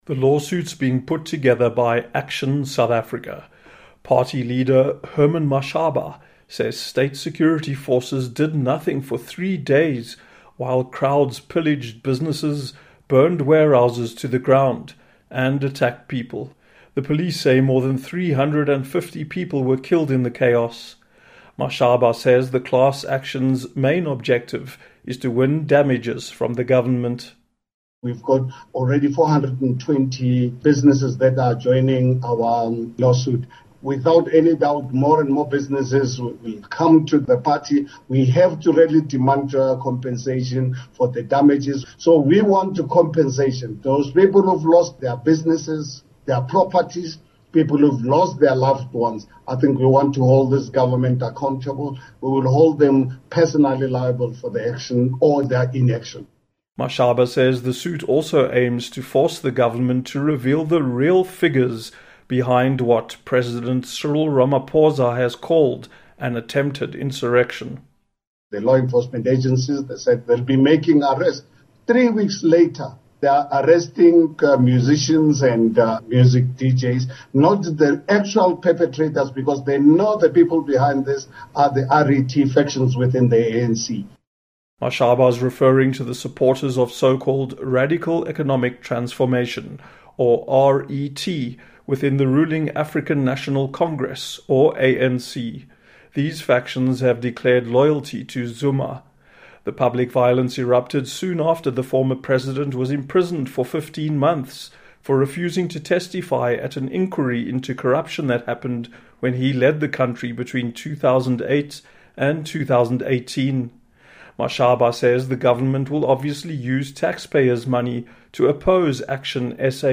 spoke with Herman Mashaba, ActionSA party leader, about the lawsuit.